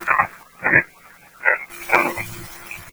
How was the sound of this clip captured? at Hills Cemetery, a place we both visit often.